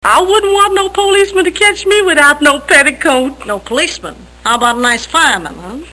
Tags: Mae West Mae West movie clips Come up and see me some time Mae West sound Movie star